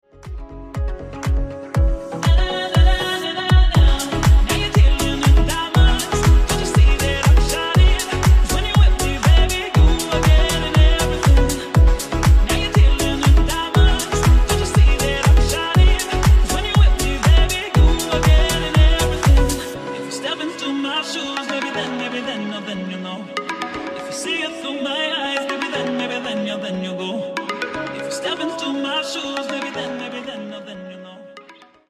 • Качество: 128, Stereo
deep house
Electronic
спокойные
красивый голос